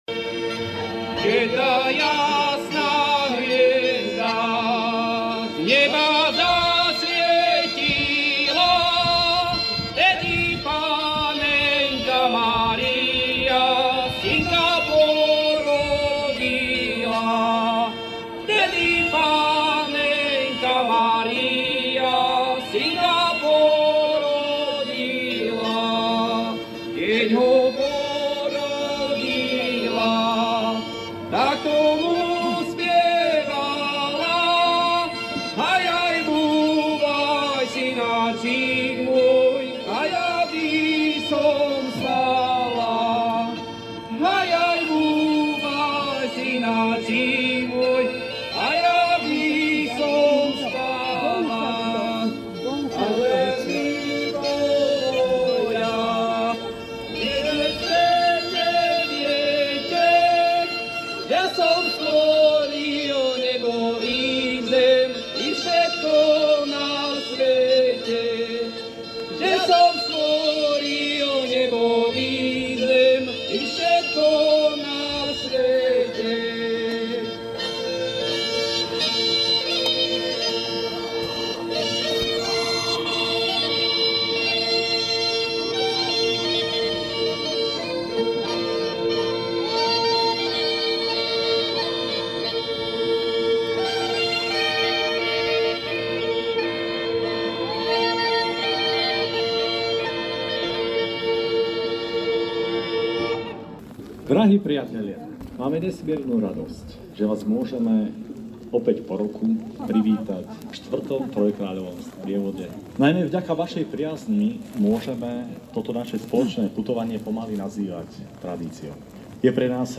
Trojkráľový sprievod v Bratislave sa 6.1.2024 konal už štvrtýkrát.
Okrem zinscenovania príbehu o symbolickom odovzdaní darov troch kráľov malému Ježiškovi zneli aj koledy, gajdy, príhovor organizátorov a podujatie zavŕšil koncert známej vokálnej skupiny Sklo.